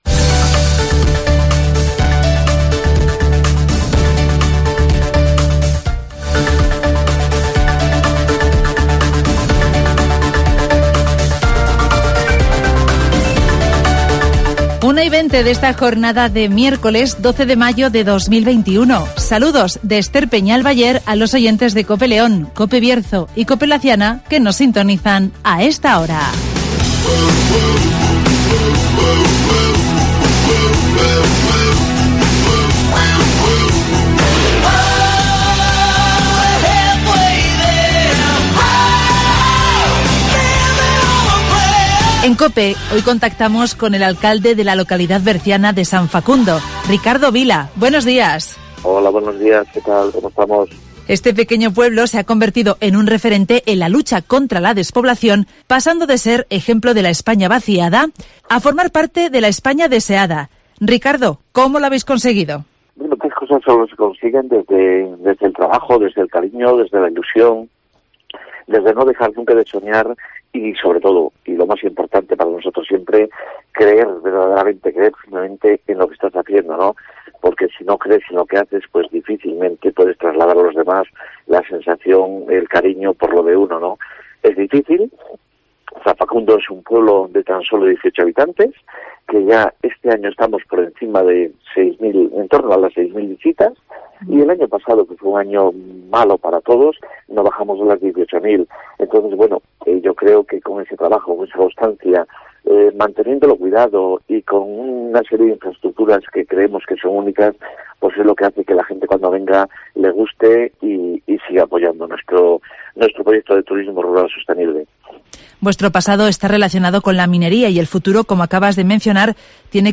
El pueblo berciano de San Facundo, ejemplo de la 'España Deseada', estrena himno y bandera (Entrevista